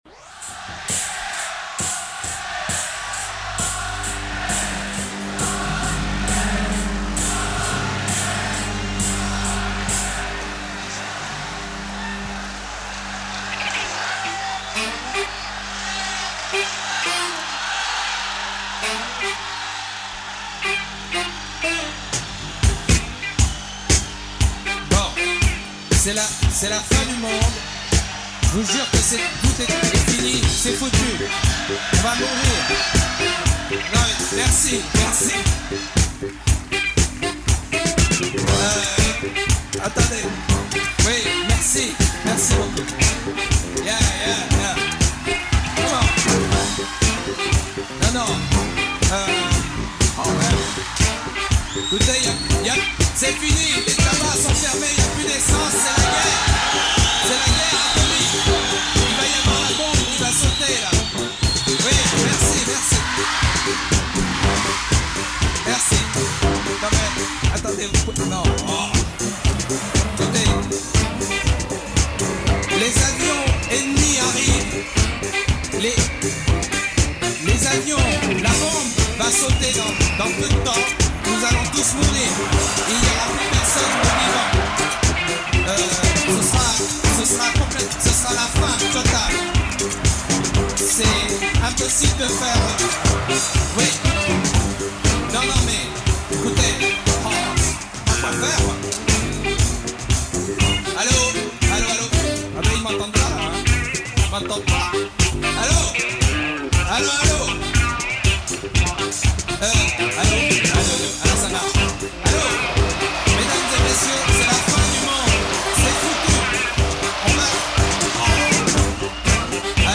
French enigmatic singer
Post punk funk and French bizarre